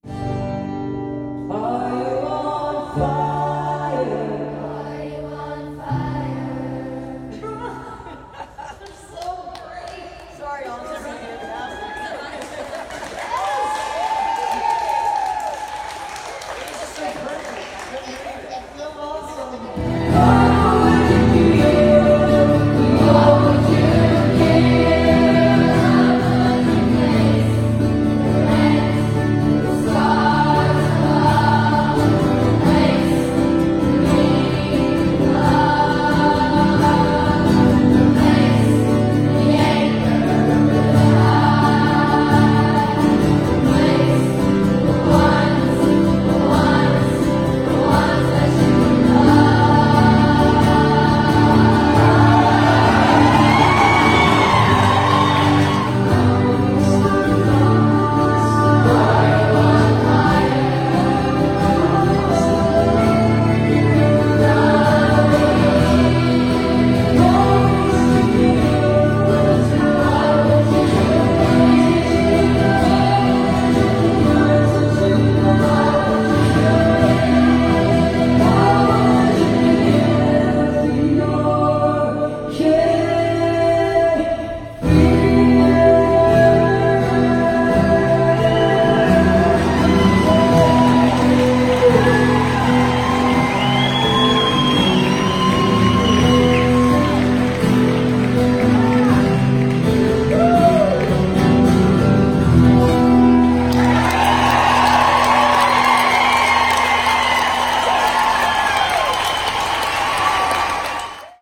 (captured from the youtube videos)
(rehearsal)